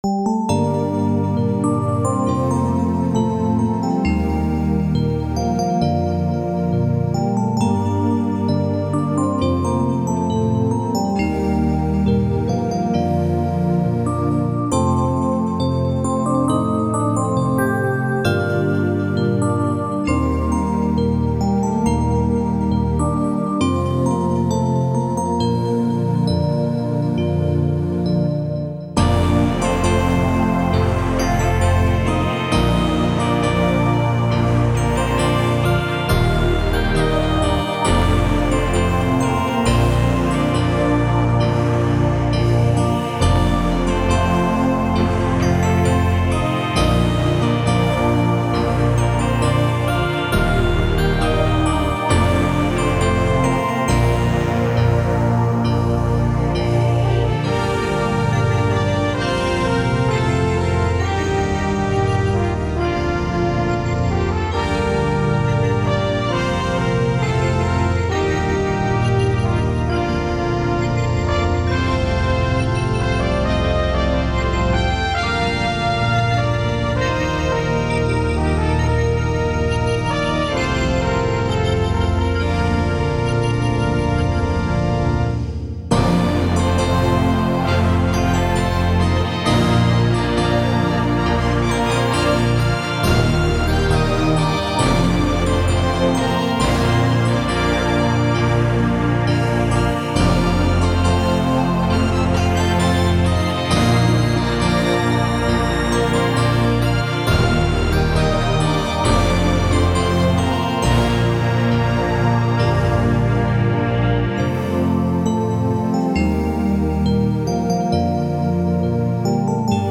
pomalu, melancolie a oslava, orchestr